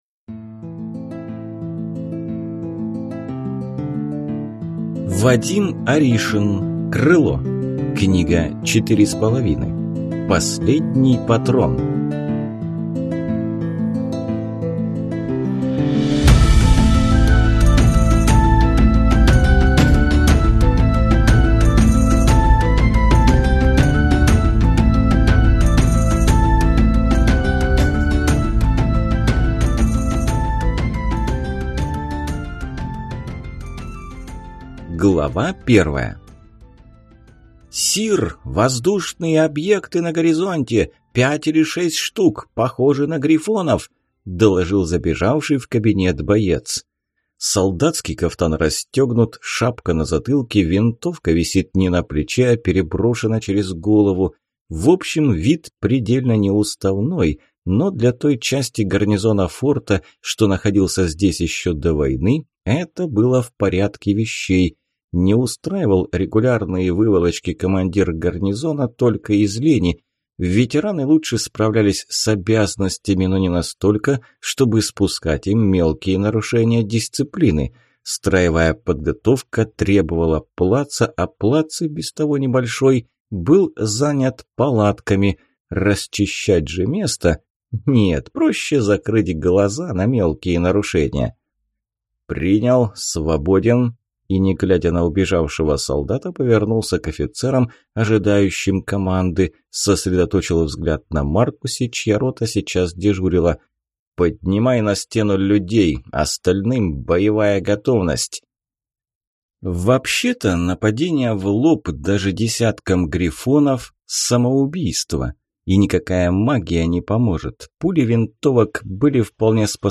Аудиокнига Крыло. Книга 4.5. Последний Патрон | Библиотека аудиокниг